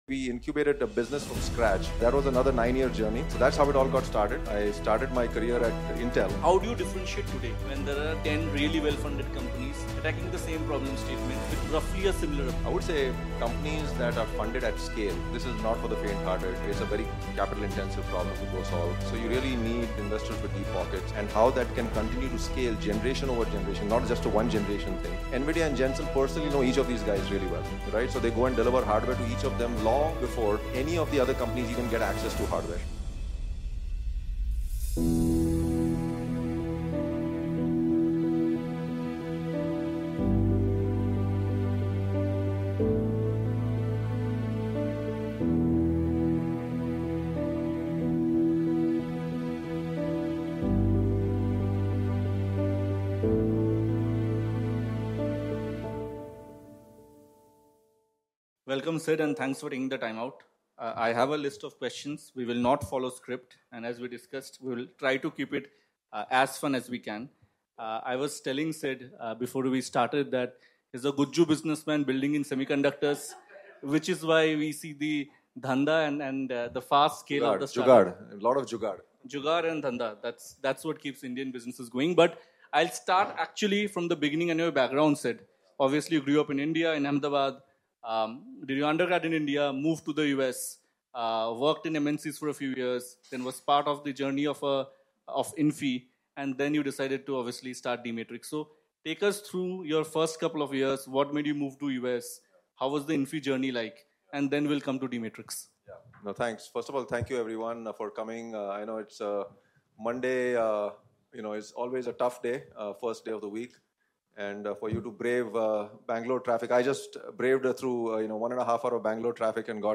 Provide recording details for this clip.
The DMII: Seed to Silicon edition in Bangalore brought together builders in India’s semiconductor story.